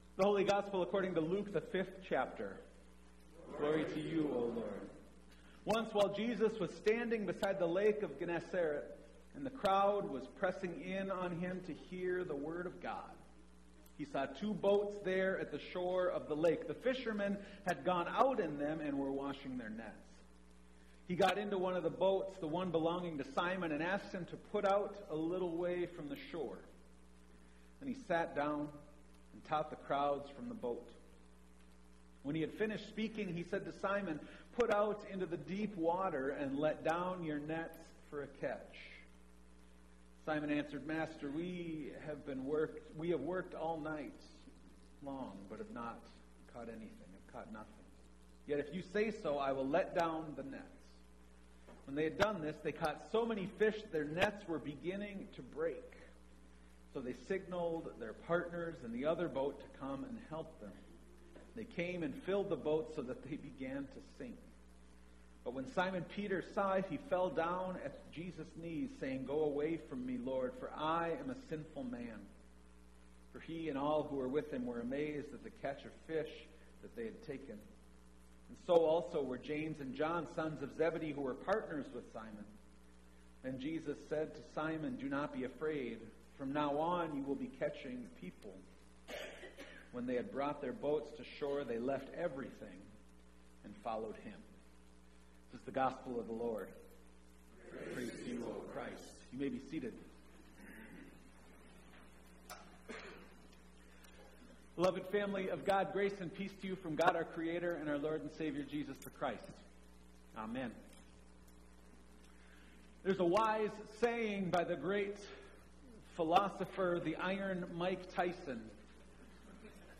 Calvary Lutheran Church Sermon Podcasts